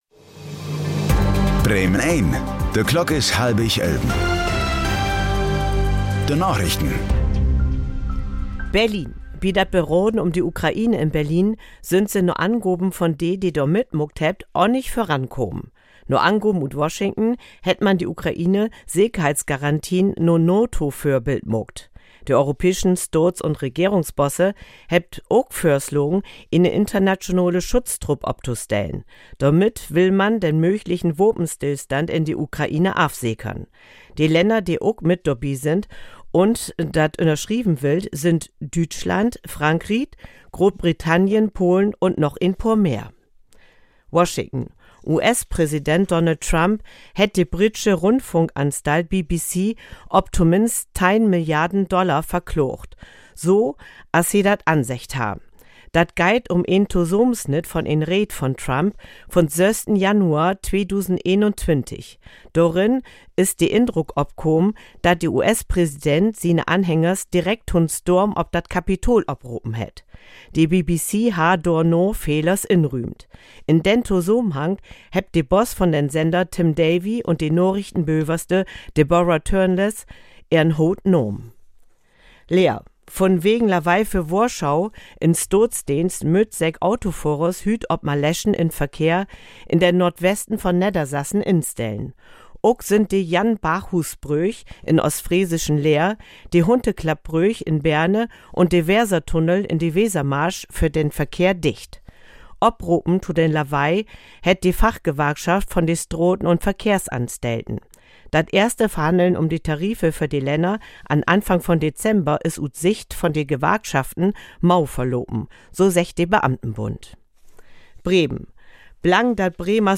Plattdüütsche Narichten vun'n 16. Dezember 2025